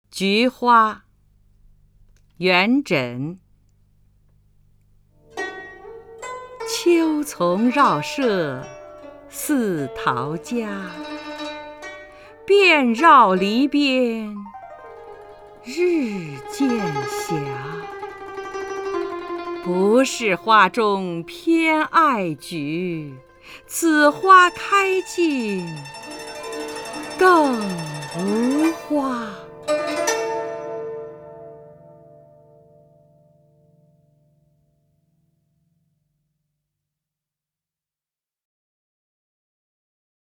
张筠英朗诵：《菊花·秋丛绕舍似陶家》(（唐）元稹)　/ （唐）元稹
名家朗诵欣赏 张筠英 目录